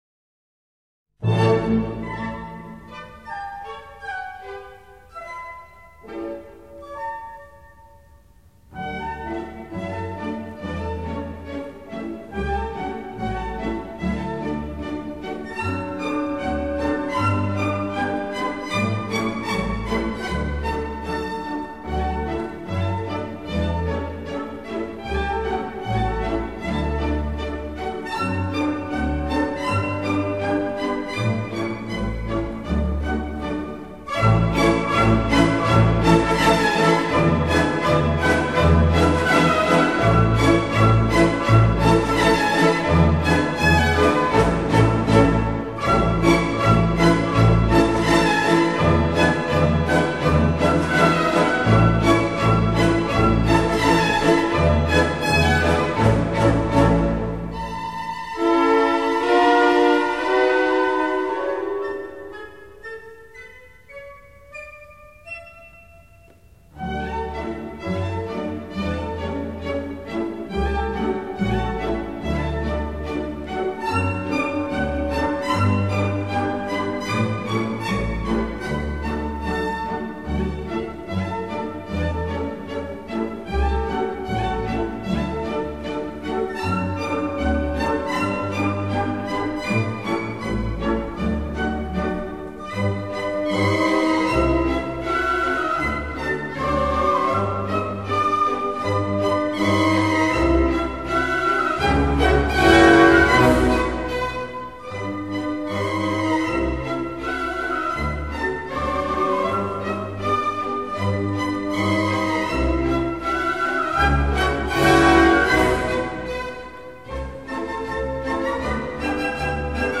C'est de la Bohême que nous vient cette polka pleine de vie, de feu, cette musique au rythme unique que l'on ne peut confondre avec nulle autre et qui connaît, même au XXème siècle, un succès considérable.
Annen Polka (La Polka pour Anna) est ravissante et gracieuse, et l'on comprend qu'elle ait particulièrement plu à la reine Victoria d'Angleterre et à ses sujets : Johann Strauss fils se trouvant alors au royaume de sa Gracieuse Majesté dut - incroyable mais vrai !
annen polka.mp3